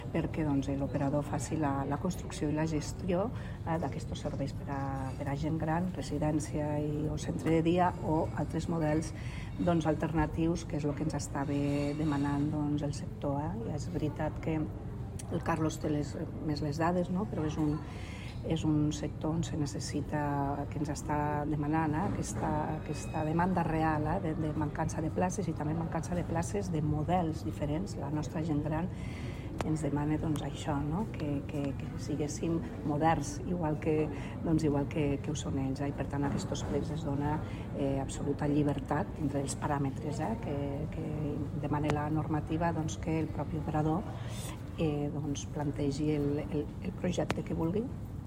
Tall de veu de la tinenta d'alcalde Begoña Iglesias sobre el nou model innovador que la Paeria promou per a l'equipament residencial a Pardinyes.